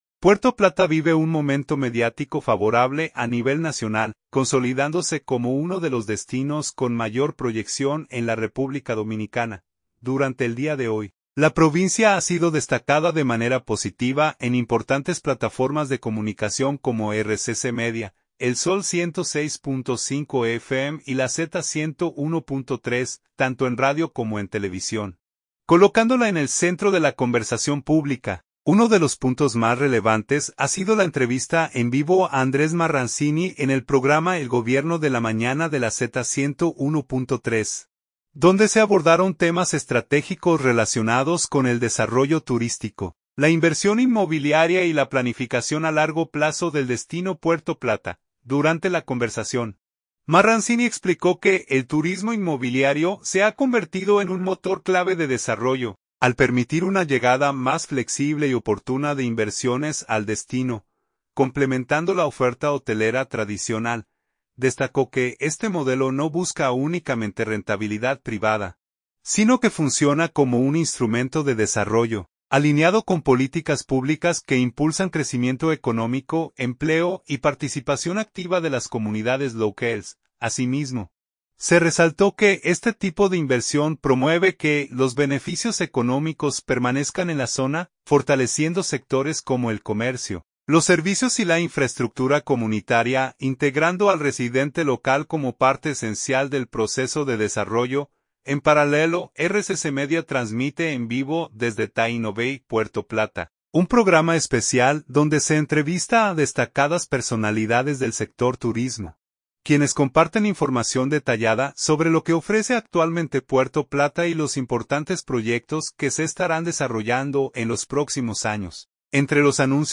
Uno de los puntos más relevantes ha sido la entrevista en vivo